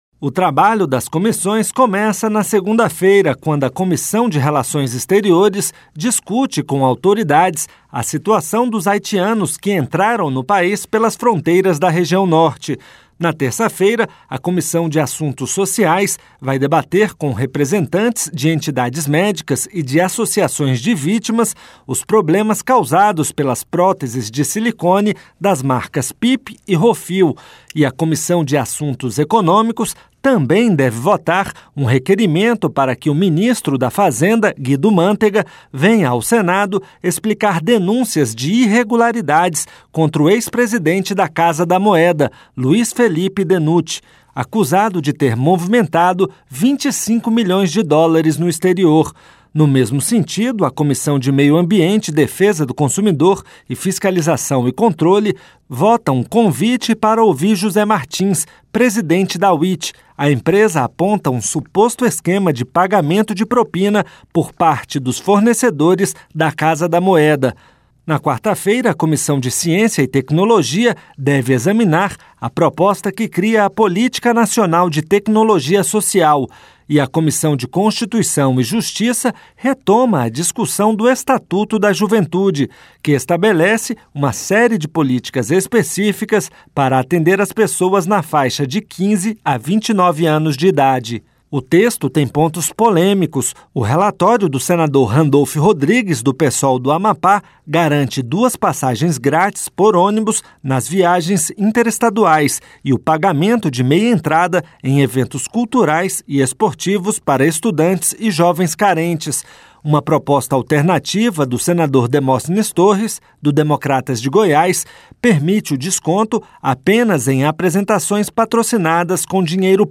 (Repórter) O trabalho das comissões começa na segunda-feira, quando a Comissão de Relações Exteriores discute com autoridades a situação dos haitianos que ingressaram no país pelas fronteiras da região norte.